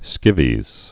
(skĭvēz)